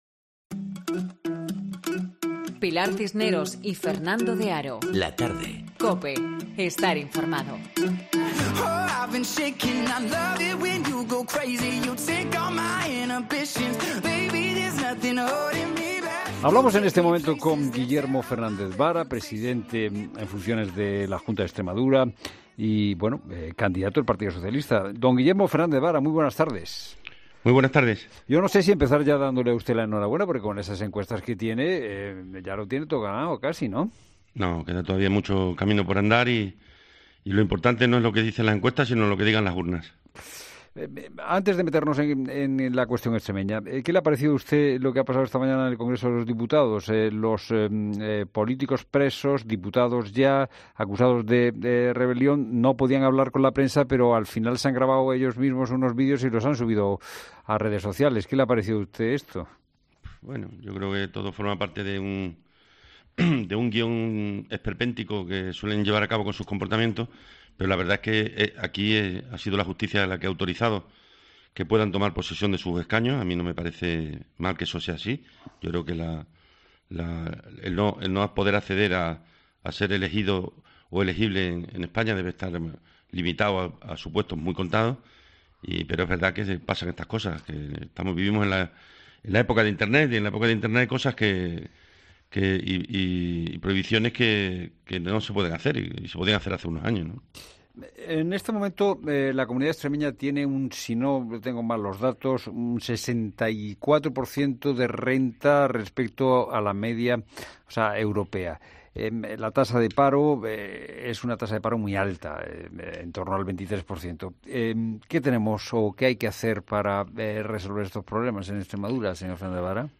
El candidato del PSOE y actual presidente en funciones de la Junta de Extremadura, Guillermo Fernández Vara, ha estado en 'La Tarde' de COPE para hacer balance de su legislatura y también comentar cómo afronta la cita electoral del próximo domingo 26 de mayo.